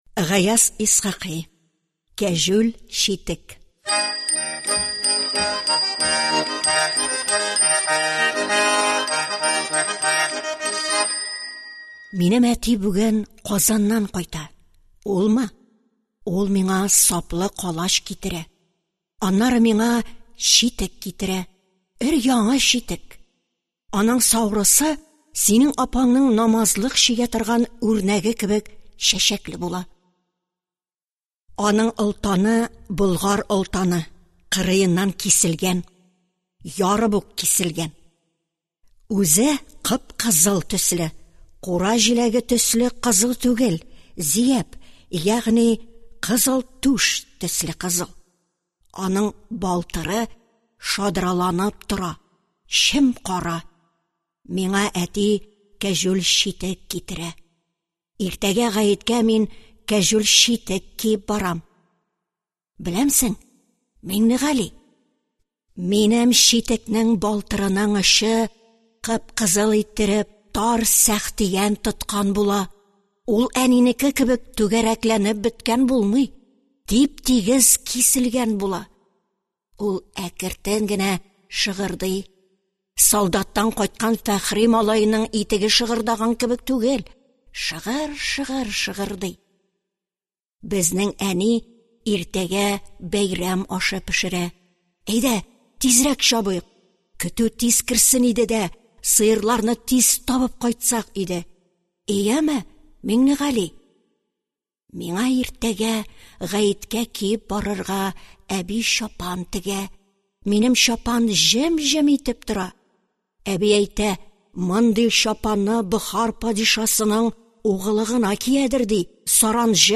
Аудиокнига Кәҗүл читек | Библиотека аудиокниг